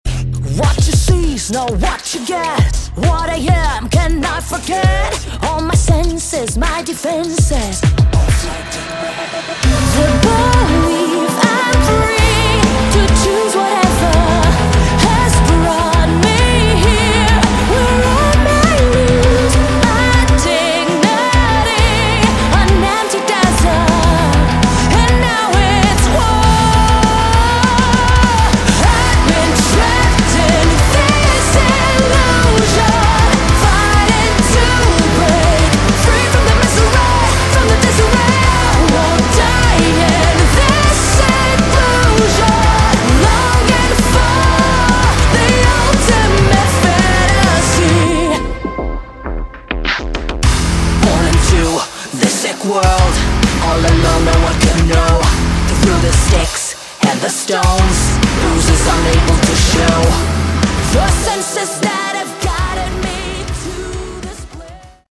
Category: Melodic Metal
vocals
guitars, bass, synths/electronic arrangements
drums